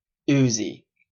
The Uzi ( /ˈzi/
En-ca-uzi.oga.mp3